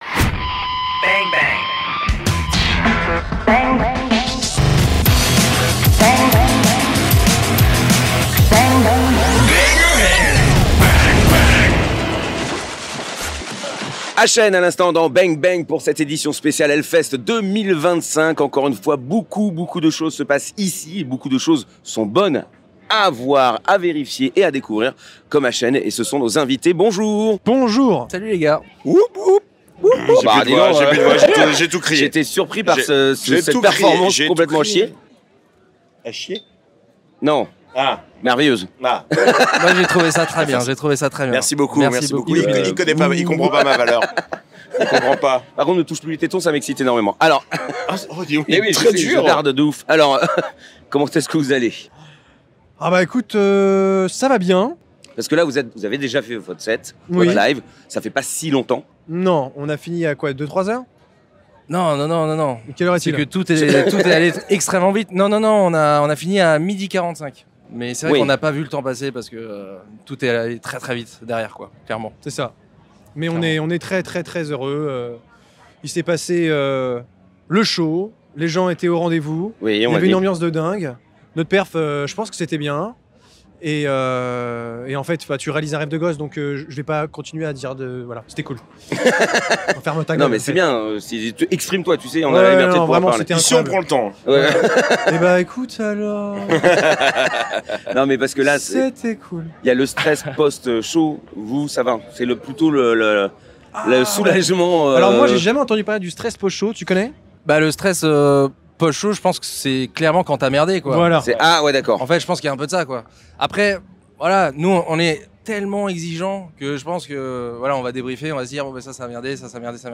Voilà encore une édition de HELLFEST accomplie ! Et nous avons fait 23 interviews !